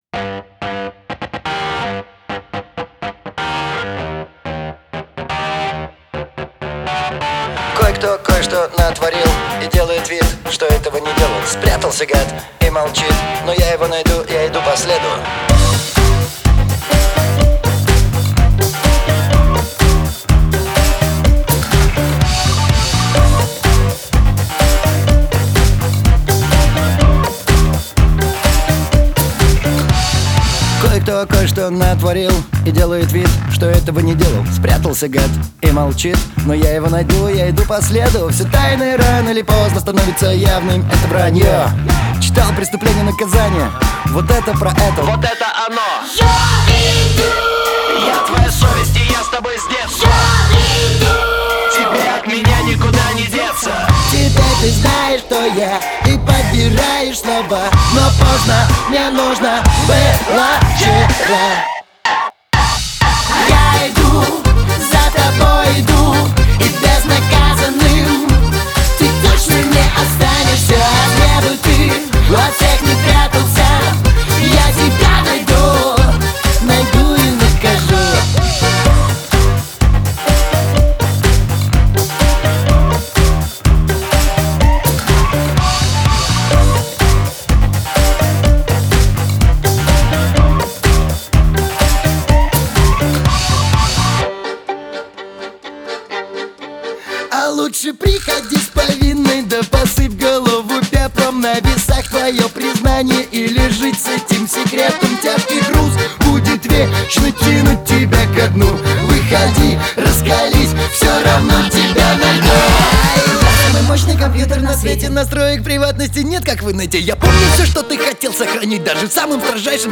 Саундтреки